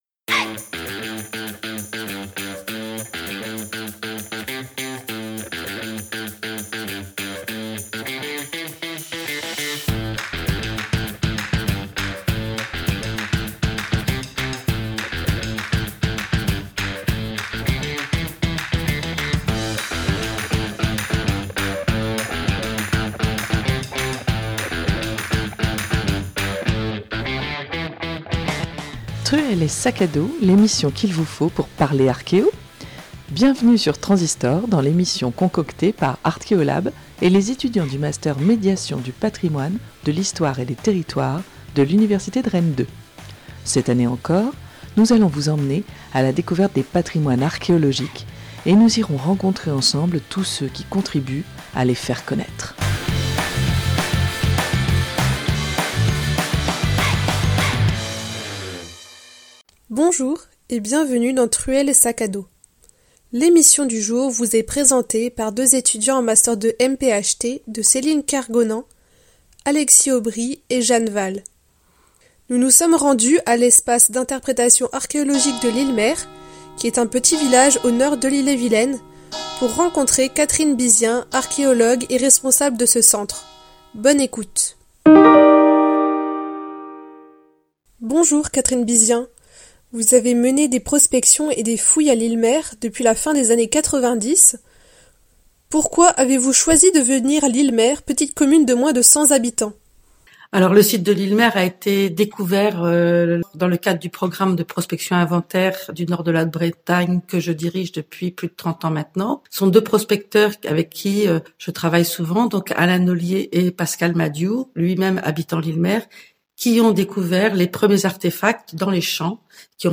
Dans cet épisode de Truelle et sac à dos, visite de l'espace d'interprétation de Lillemer, un site néolithique entre Dol-de-Bretagne et Saint-Malo (Ille-et-Vilaine). La médiation a été assurée par les archéologues qui ont décrit leur démarche scientifique lors des fouilles, notamment d'une nécropole.